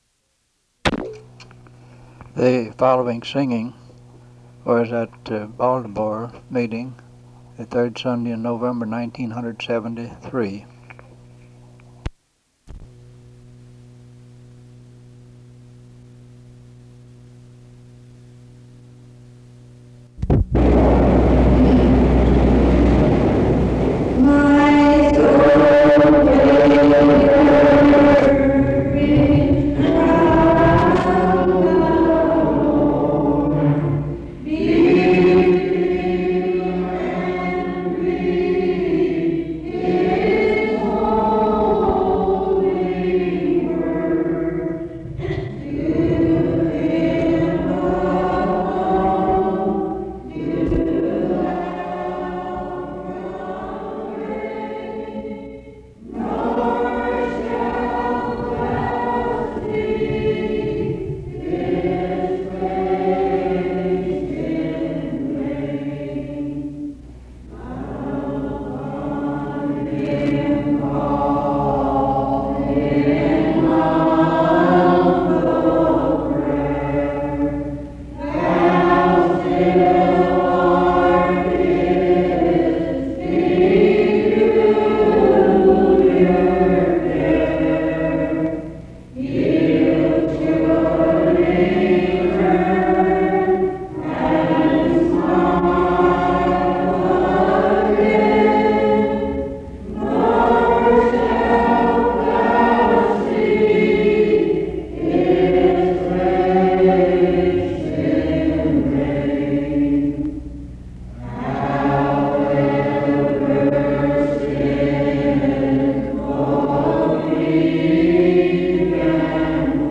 Singing portion
Hymns Primitive Baptists